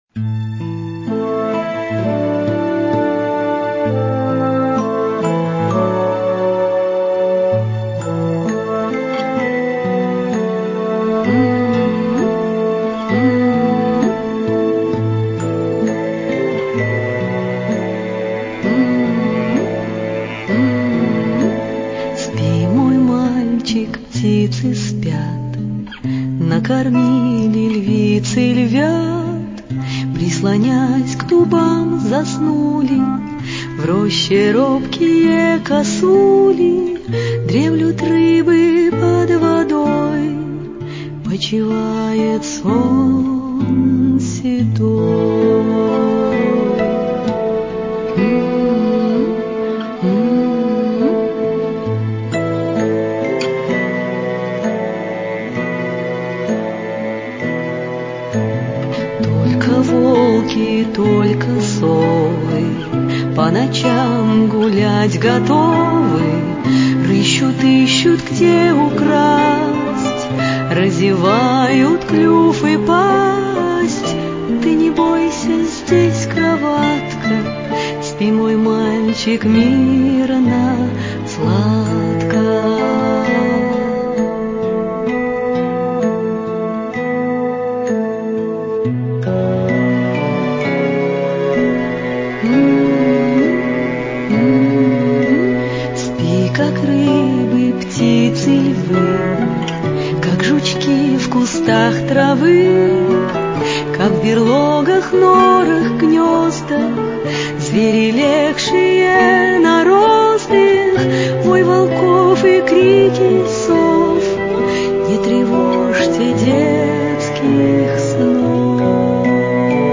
• Качество: Хорошее
• Категория: Детские песни
🎶 Детские песни / Колыбельные песни